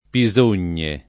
[ pi @1 n’n’e ]